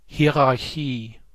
Ääntäminen
Ääntäminen US Tuntematon aksentti: IPA : /ˈhaɪə.ɹɑː.kɪ/ IPA : /ˈhaɪɹ.ɑːɹ.kɪ/ Haettu sana löytyi näillä lähdekielillä: englanti Käännös Ääninäyte Substantiivit 1.